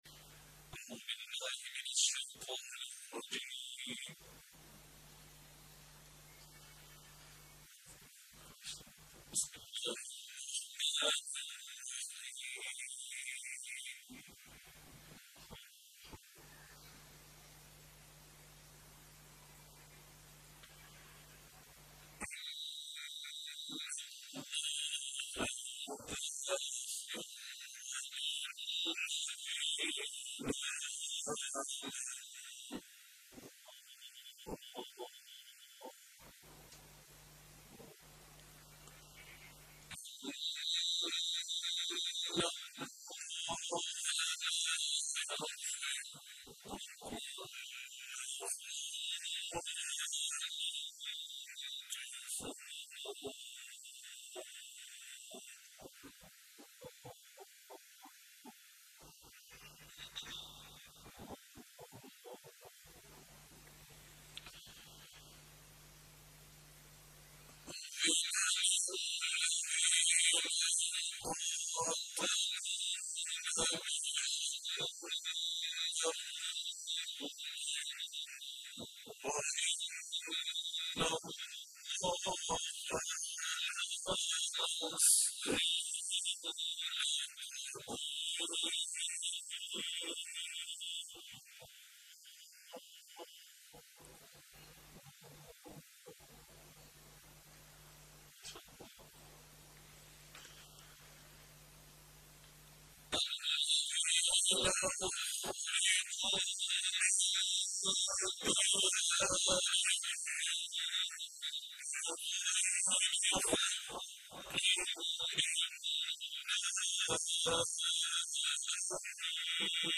تلاوت های برتر